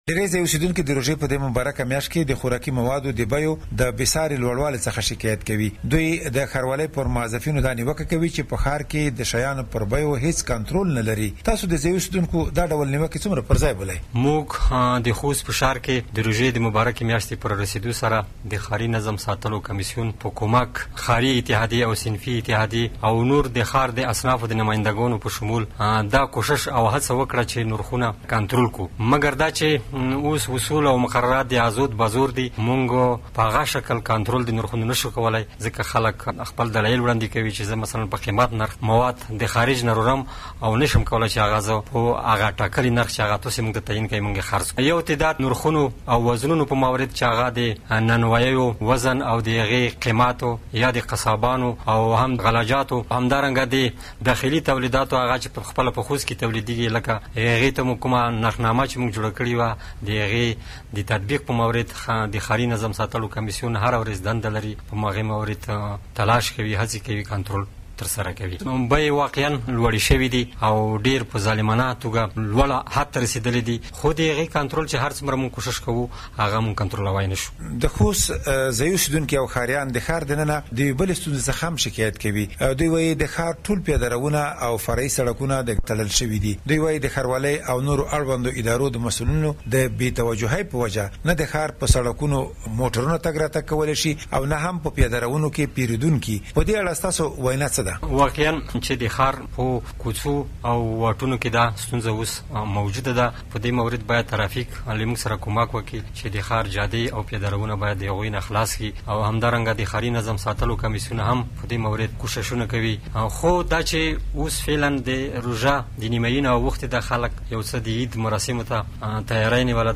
د خوست له ښاروال سره مرکه